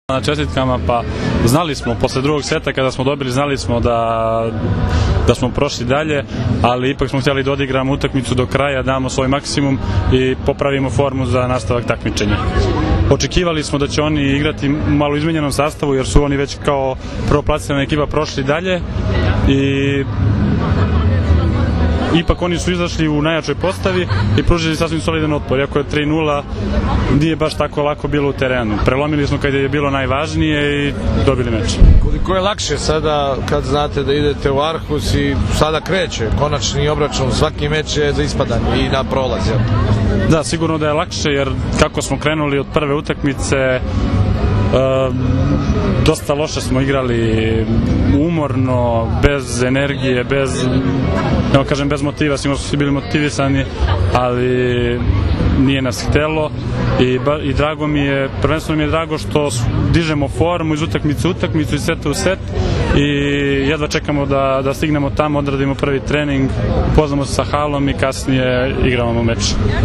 IZJAVA SREĆKA LISINICA